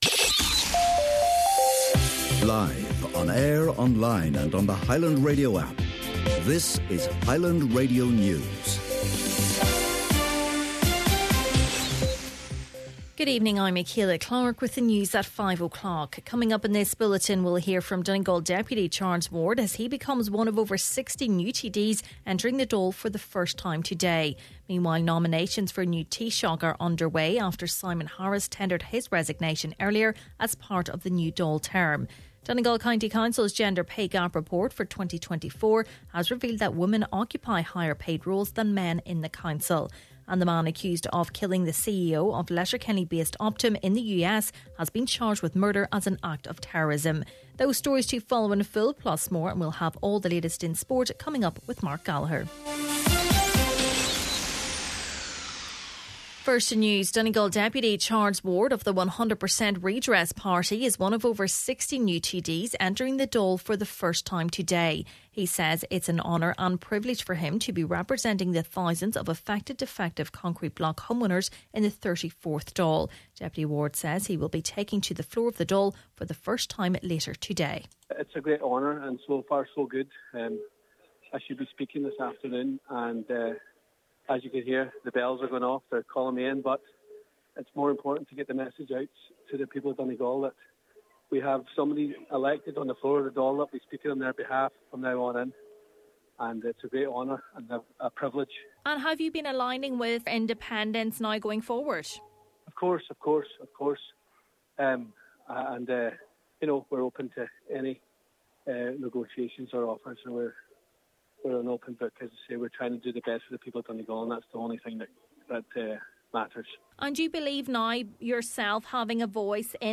Main Evening News, Sport and Obituaries – Wednesday December 18th